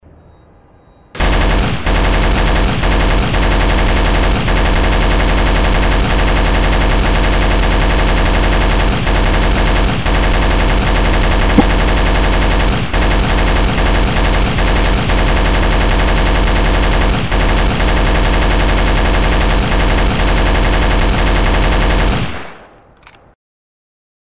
Звуки пулемёта
Звук непрекращающейся пулеметной стрельбы